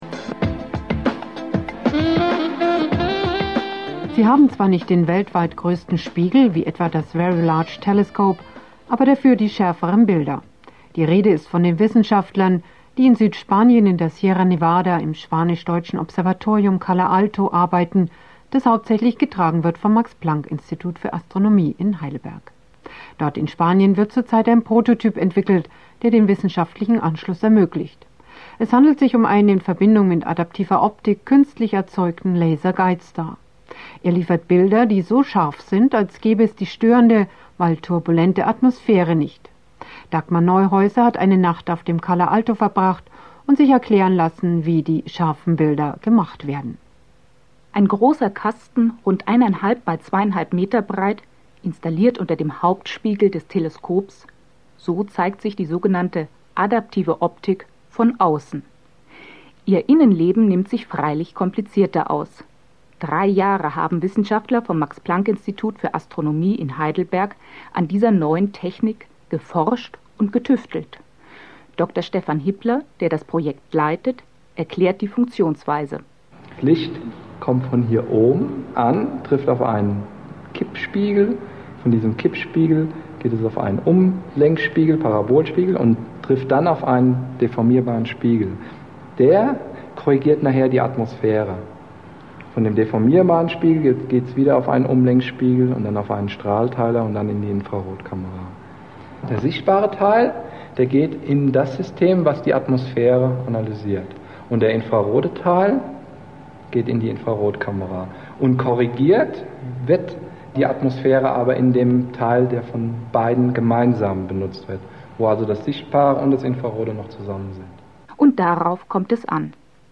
Radio Sendung (Radius) des Bayerischen Rundfunks (Bayern2Radio) vom 8. November 1999